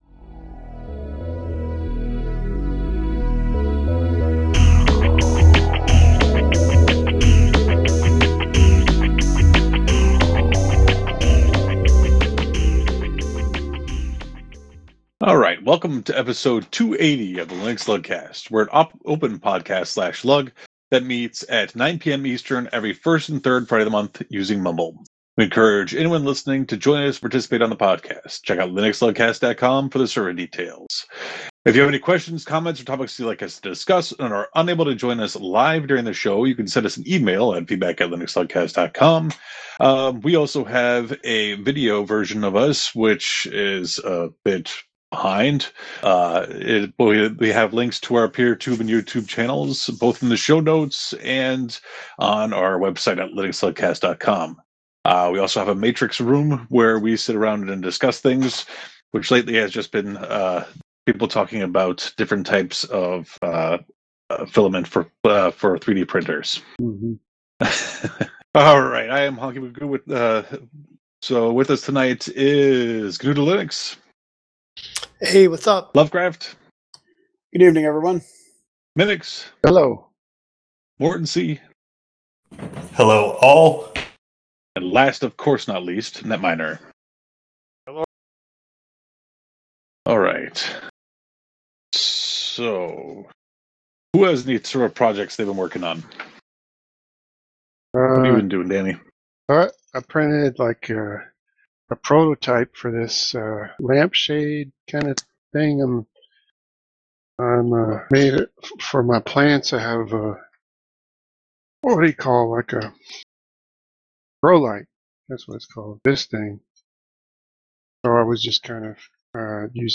Welcome to Episode 288 of the LinuxLUGcast We are an open podcast/LUG that meets at 9 PM EST every first and third friday of the month using mumble.